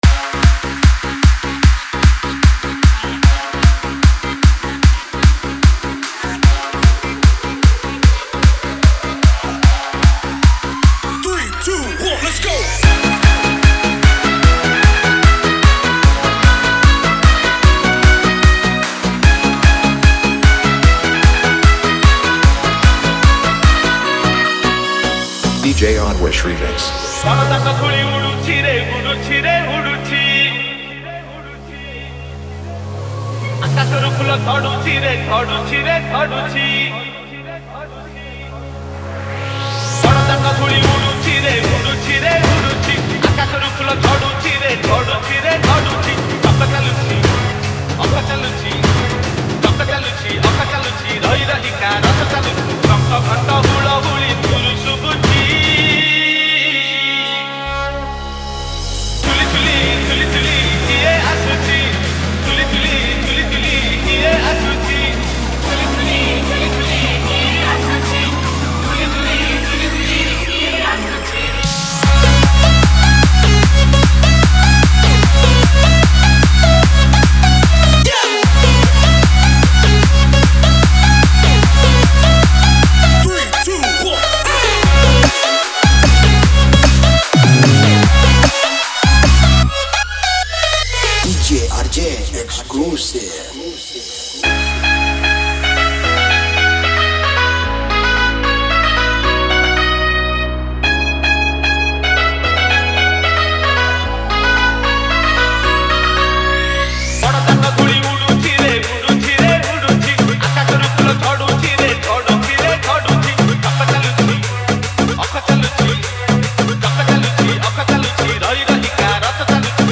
Bhajan Dj Song Collection 2022